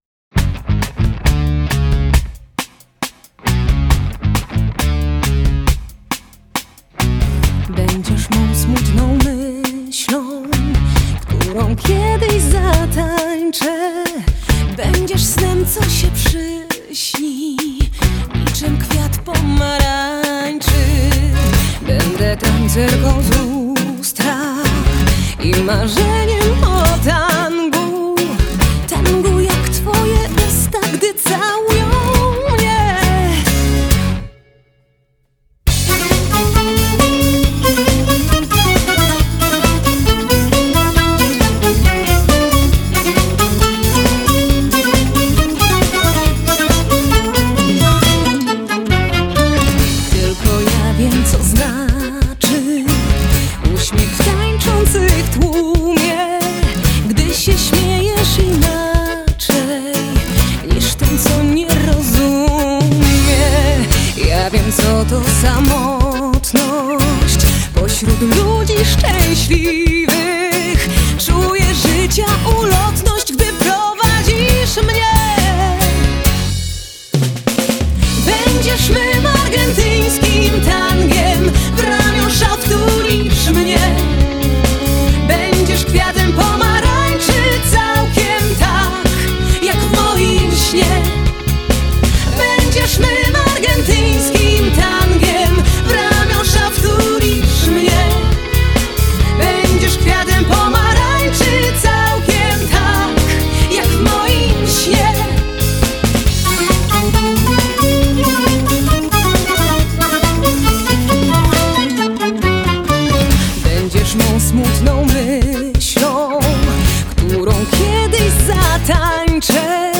Genre: Folk-Rock